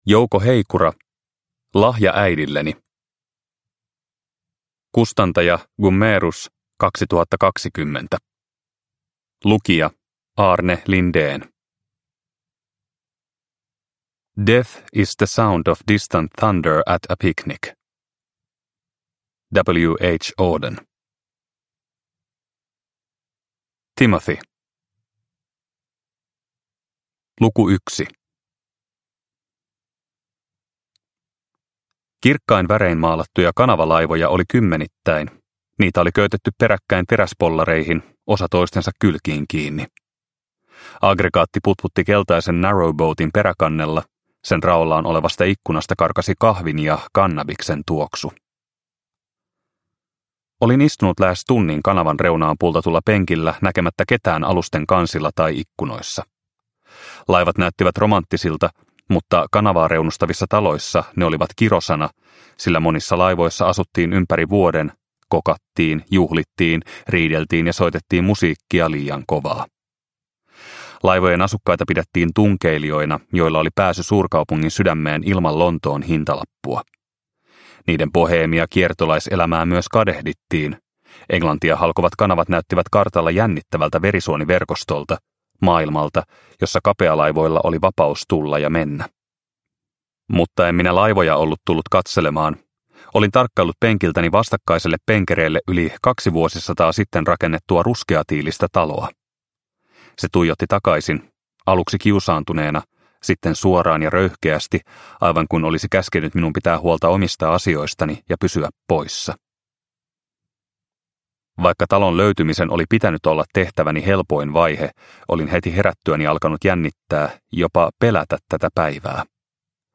Lahja äidilleni – Ljudbok – Laddas ner